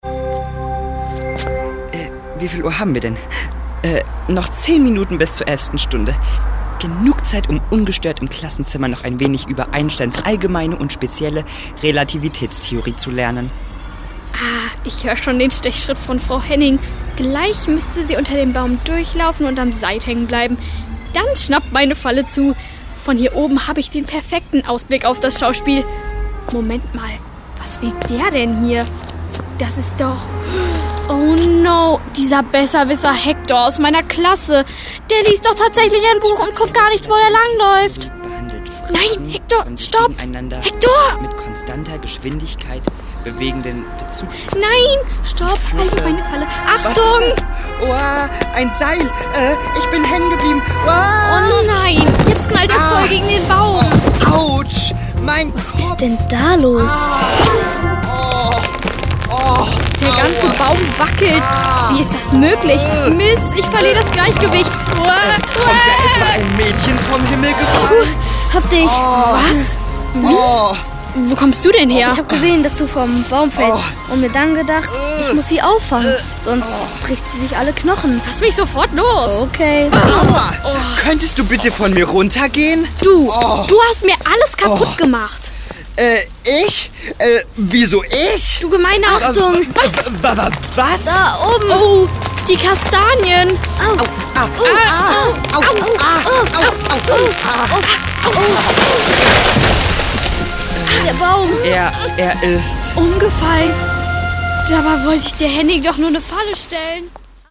Wie man Riesen besiegt - Folge 1 (MP3-Hörbuch - Download)
• Sachgebiet: Hörspiele